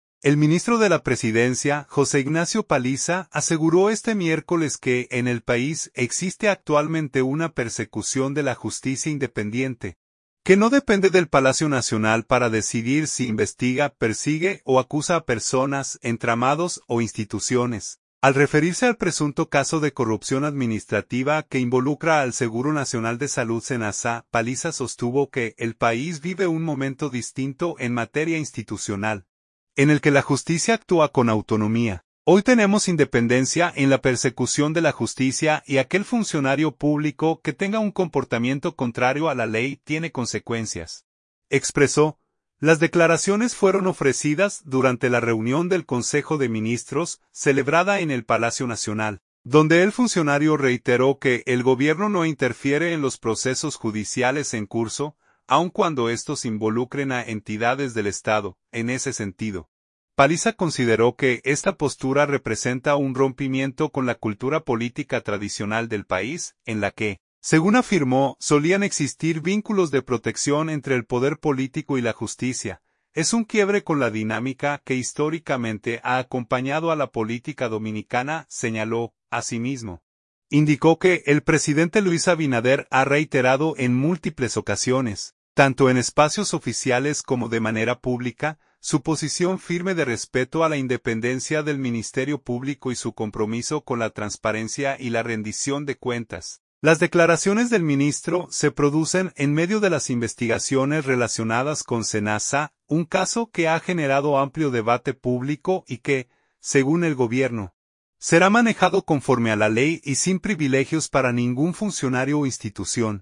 Las declaraciones fueron ofrecidas durante la reunión del Consejo de Ministros, celebrada en el Palacio Nacional, donde el funcionario reiteró que el Gobierno no interfiere en los procesos judiciales en curso, aun cuando estos involucren a entidades del Estado.